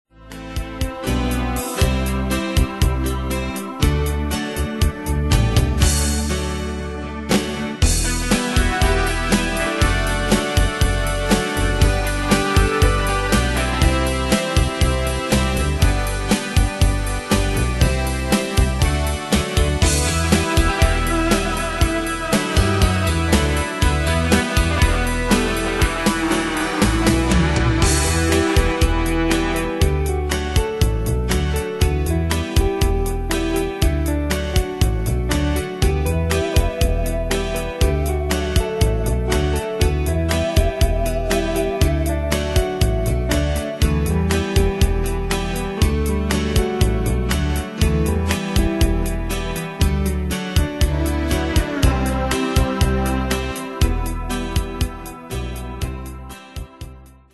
Danse/Dance: Rock Cat Id.
Pro Backing Tracks